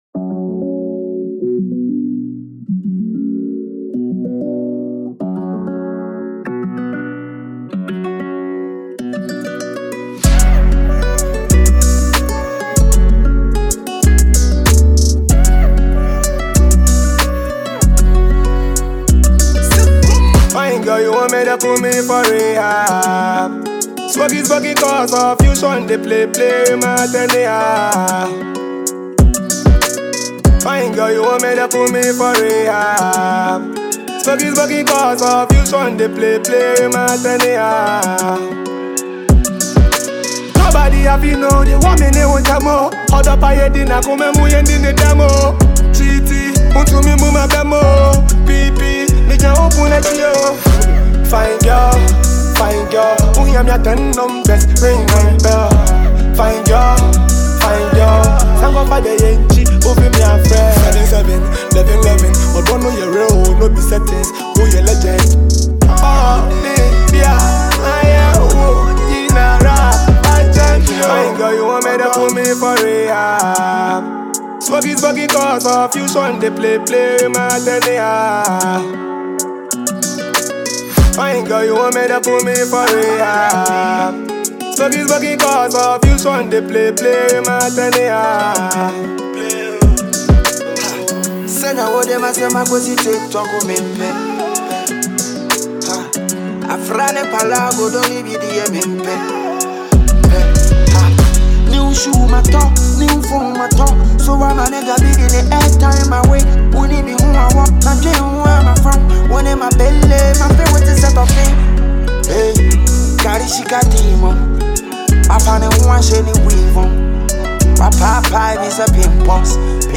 powered by bold delivery and rhythmic finesse.
smooth cadence
Enjoy this 2025 Afrobeats tune,